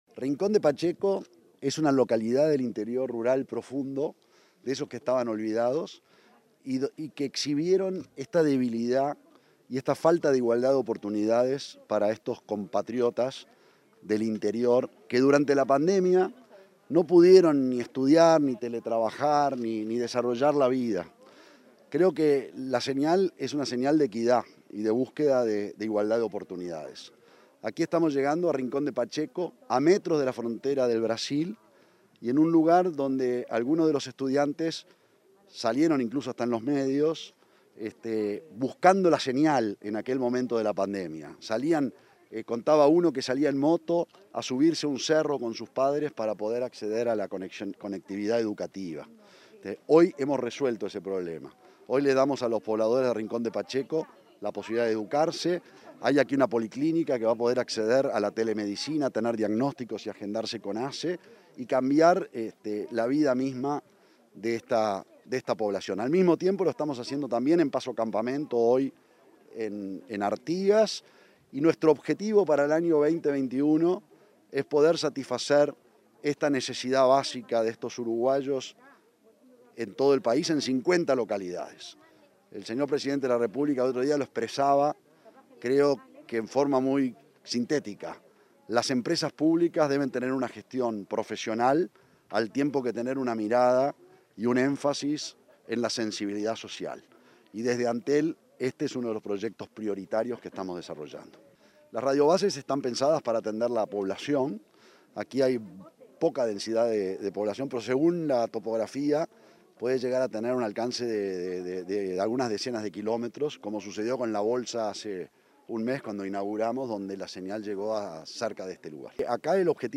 Entrevista al presidente de Antel, Gabriel Gurméndez